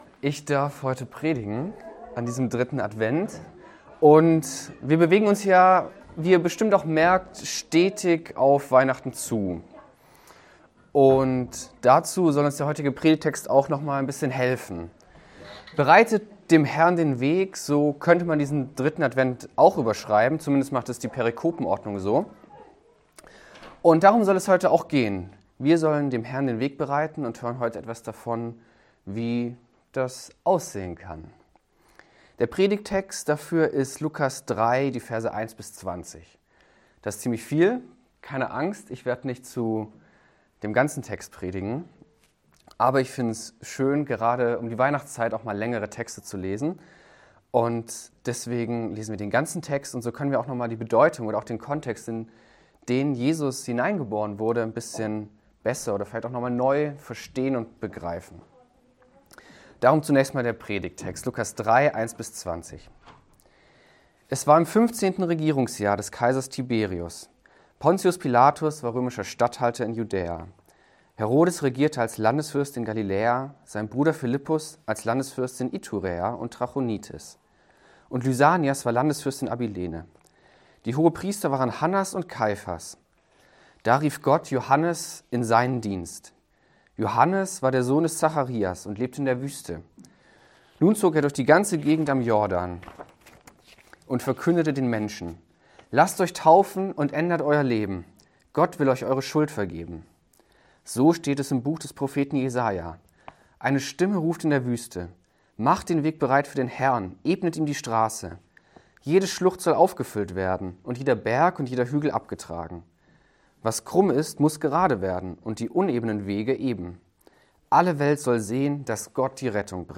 Predigtpodcast